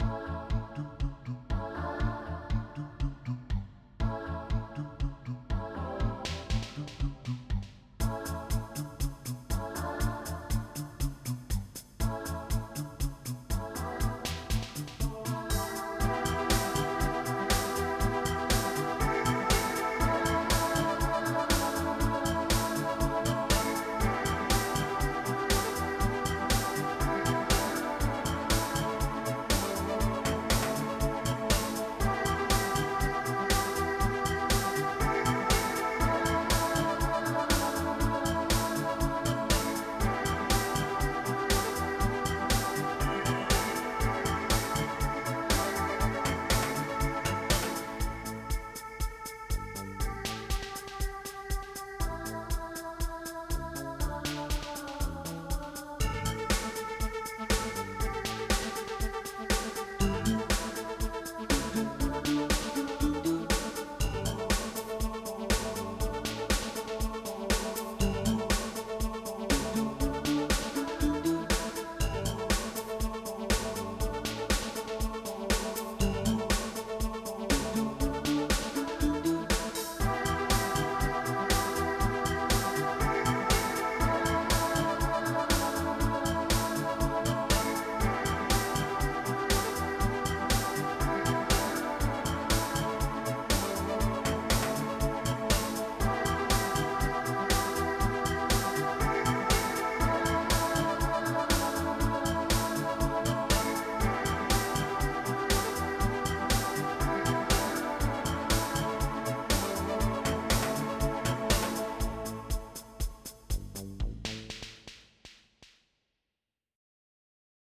MIDI Music File
SYNTHY.mp3